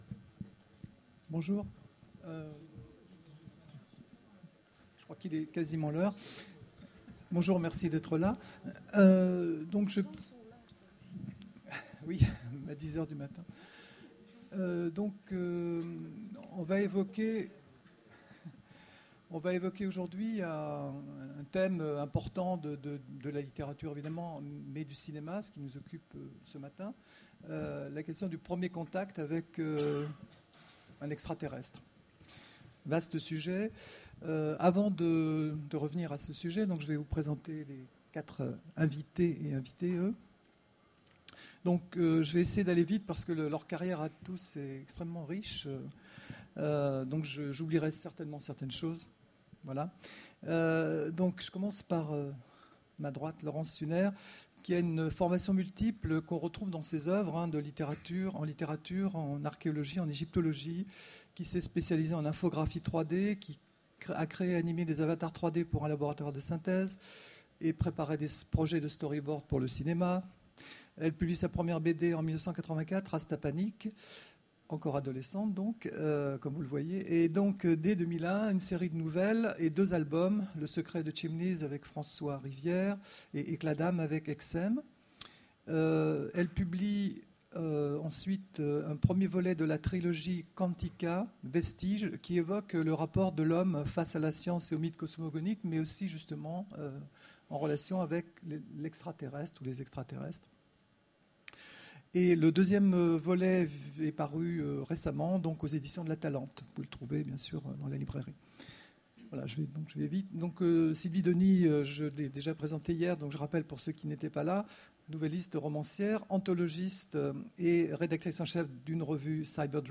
Mots-clés Extraterrestre Conférence Partager cet article